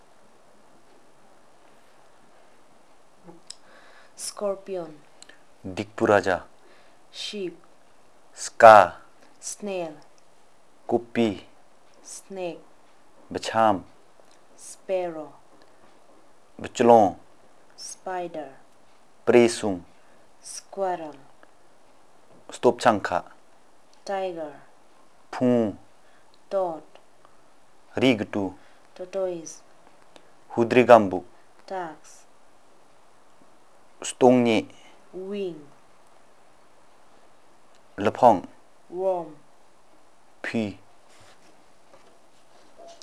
Elicitation of words about birds and related, insects and related and reptiles and rodents